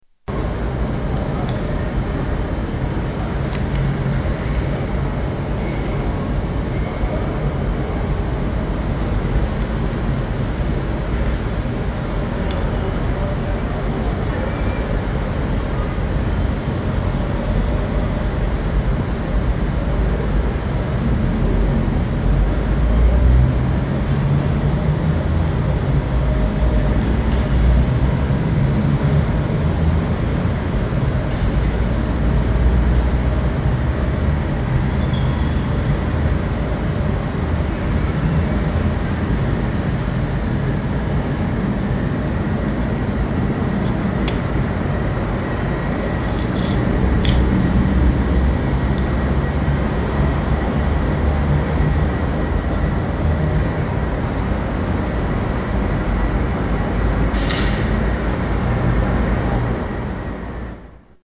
Buenos Aires Terraza roof top sounds